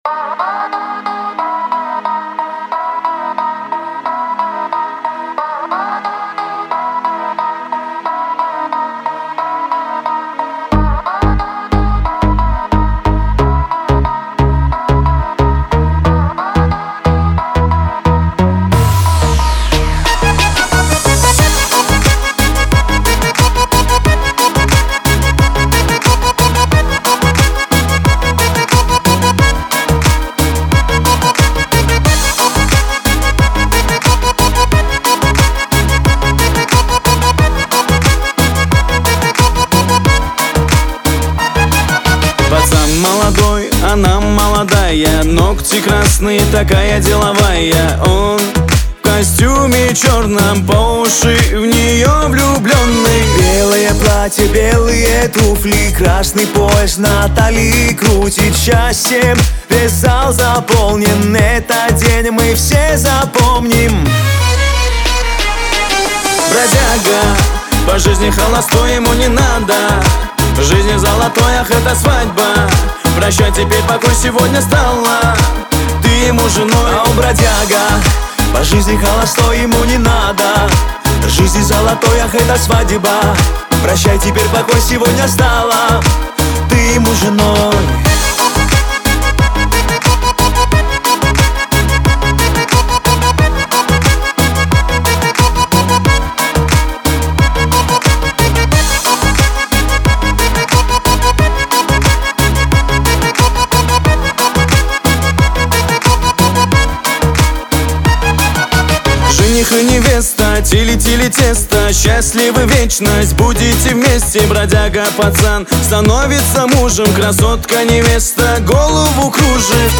Категория: танцевальная